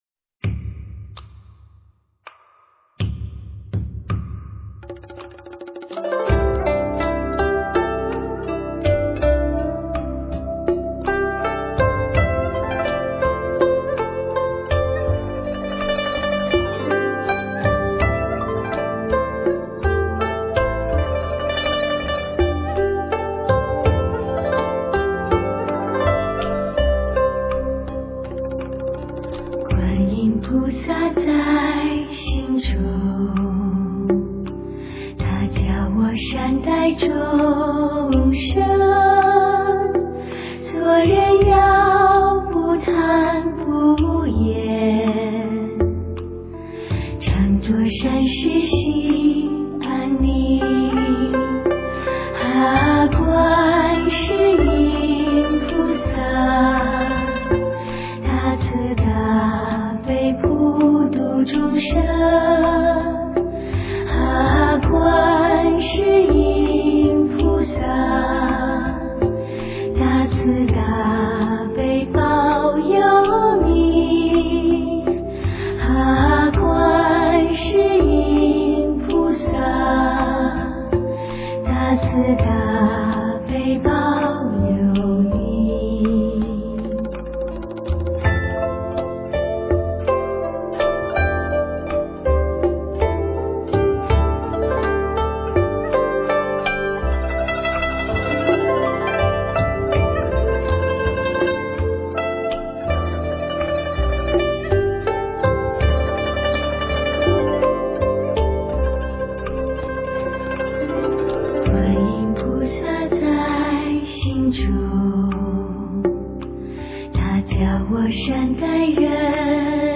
磁性与绵柔的碰撞 悦耳动听、梵音传唱、造福众生！
在继承了传统佛教音乐风格的基础上，融入了现代音乐元素，
在清新的赞佛声中感受到宁静与和谐的氛围。